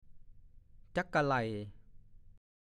ฐานข้อมูลพจนานุกรมภาษาโคราช